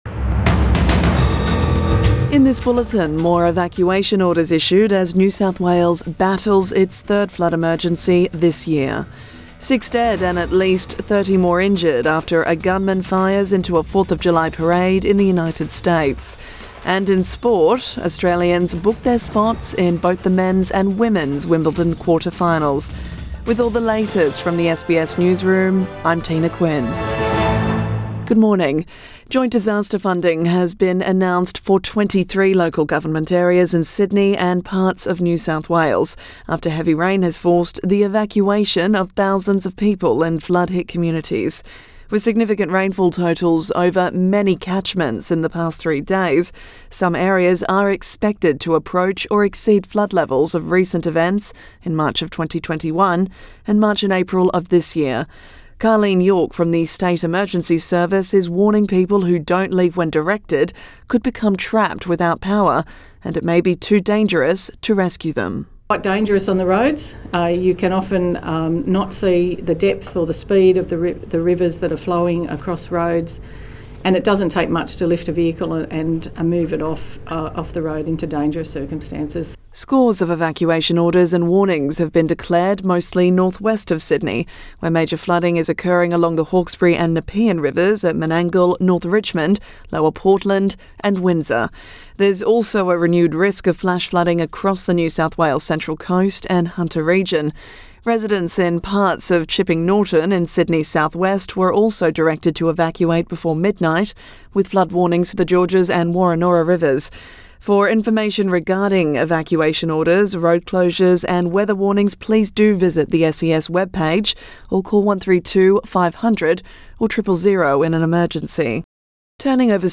AM bulletin 5 July 2022